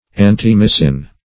antimycin \an`ti*my"cin\ ([a^]n`t[i^]*m[imac]"s[i^]n), n.